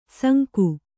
♪ sanku